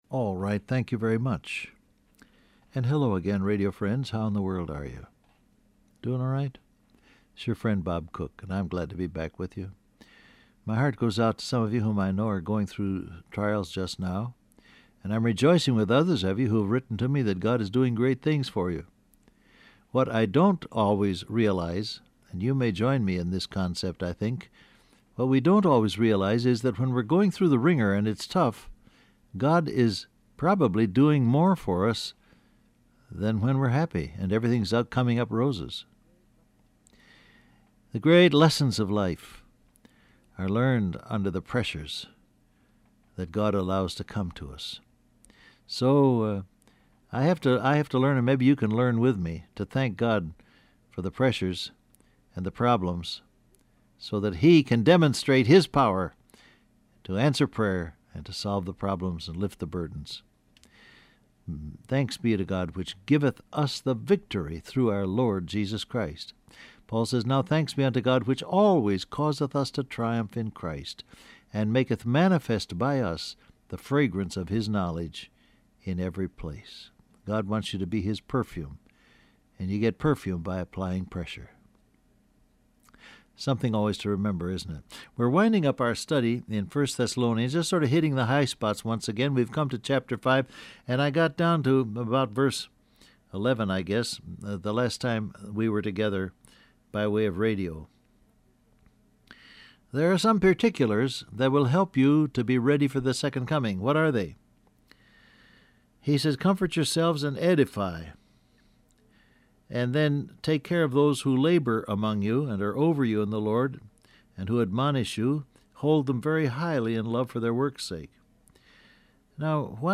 Download Audio Print Broadcast #7116 Scripture: 1 Thessalonians 5 Topics: Edify , Encourage , Prayer , Gratitude , Lifestyle Transcript Facebook Twitter WhatsApp Alright, thank you very much.